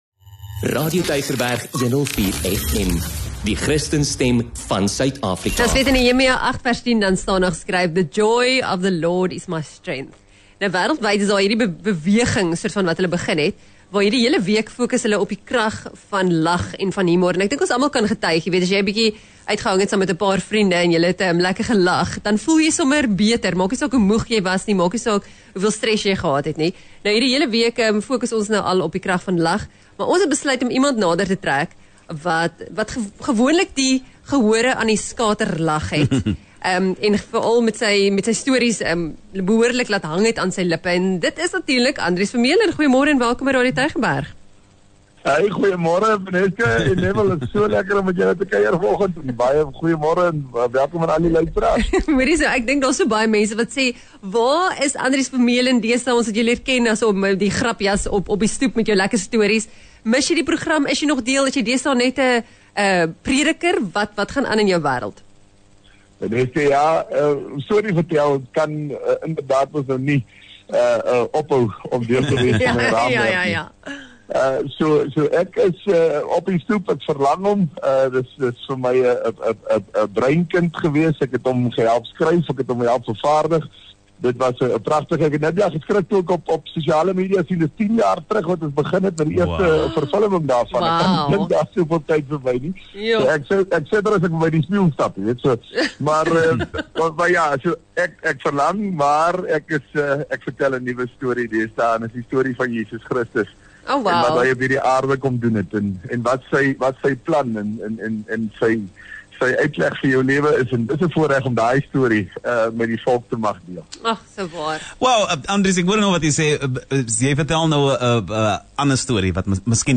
Hulle het gelê soos hulle lag vir sy stories.